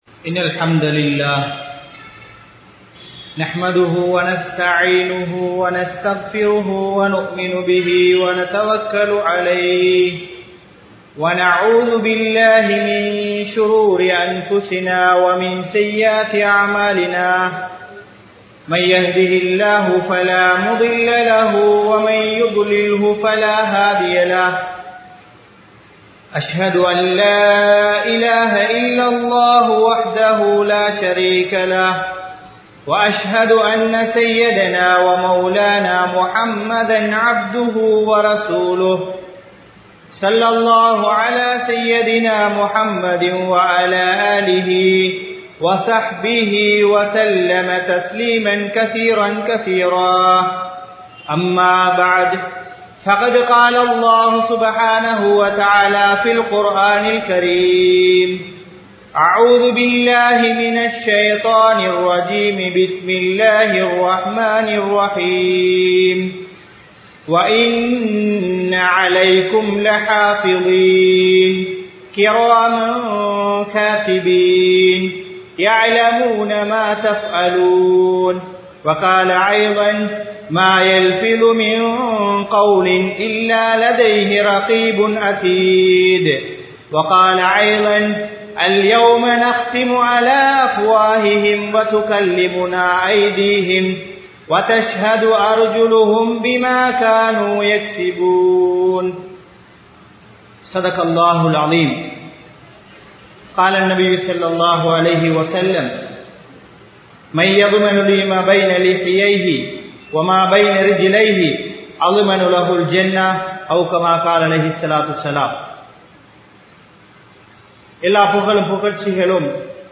Naavin Amaanithathai Paathukaarunkal(நாவின் அமானிதத்தை பாதுகாருங்கள்) | Audio Bayans | All Ceylon Muslim Youth Community | Addalaichenai